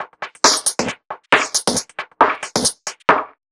Index of /musicradar/uk-garage-samples/136bpm Lines n Loops/Beats
GA_BeatRingB136-05.wav